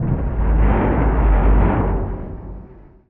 metal_low_creaking_ship_structure_11.wav